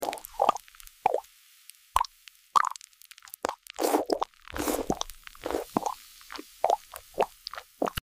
🔥❄ A surreal ASMR mukbang where noodles glow like lava and crackle like ice.